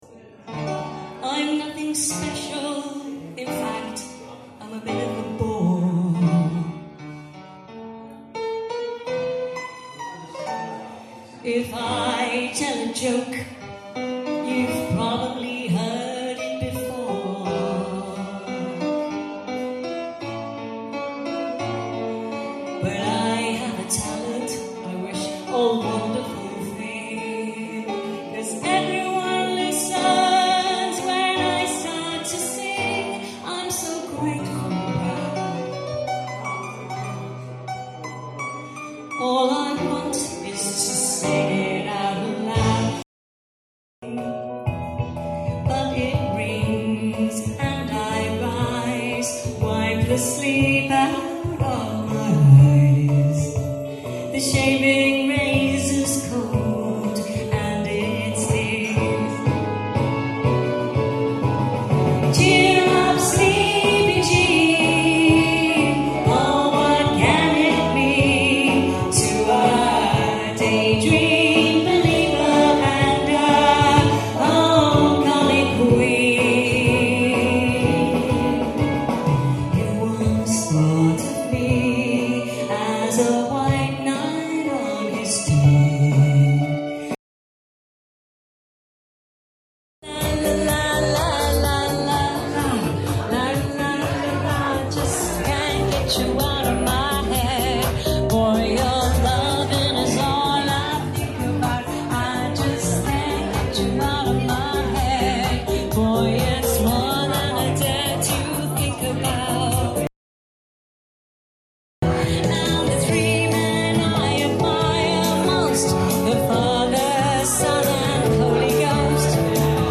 These demos are all live and unadjusted.